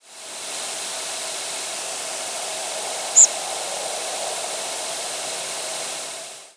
Chestnut-sided Warbler nocturnal
presumed Chestnut-sided Warbler nocturnal flight calls